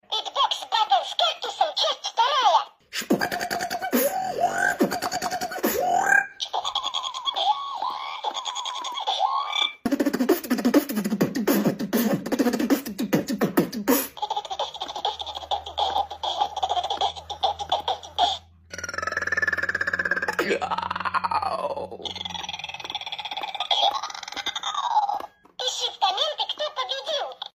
Beatbox Battle Among Us vs Cactus
Beatbox-Battle-Among-Us-vs-Cactus.mp3